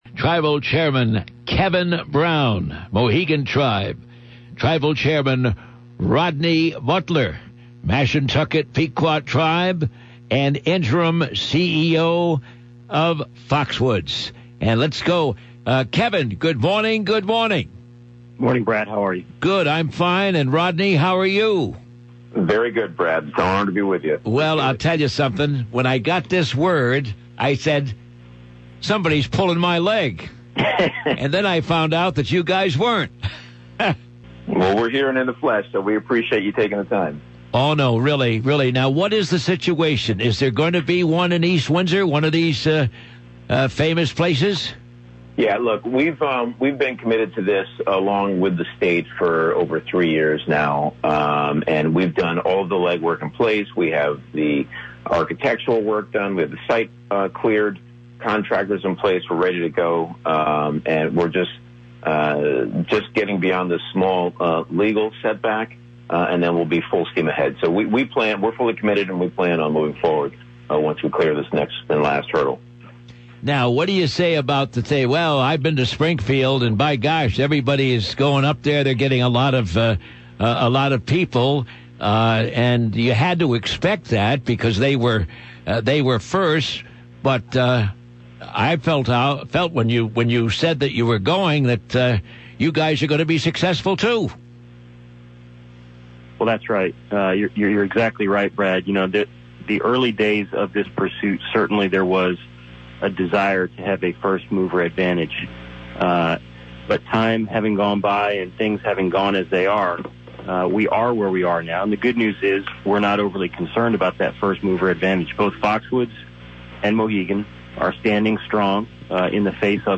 Tribal Chairman of the Mohegan Tribe, Kevin Brown, and Tribal Chairman of the Mashantucket Pequot Tribe and Interim CEO of Foxwoods, Rodney Butler, discuss the latest regarding the plans for a casino in East Windsor.